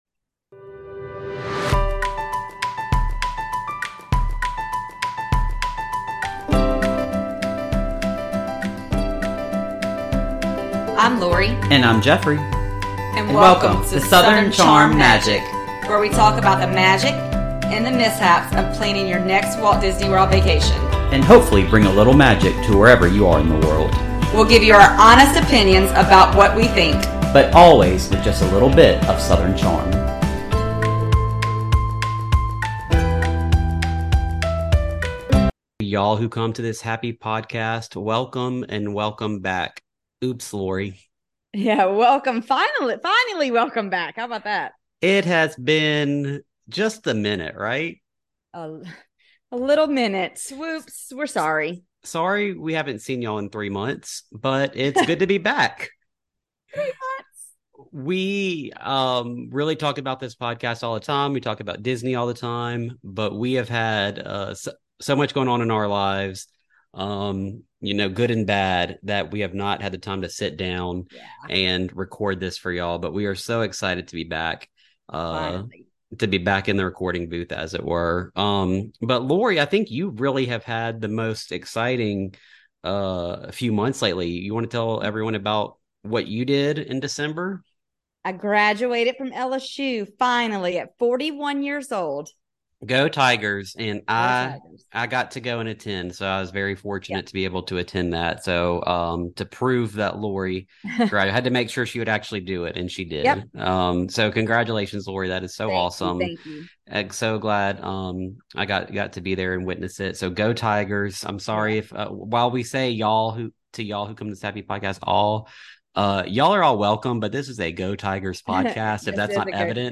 In Southern Charmed Magic, two ultimate Disney fans from South Louisiana talk about the magic and mishaps of planning a Walt Disney World vacation, with practical tips to help you plan your best Disney trip.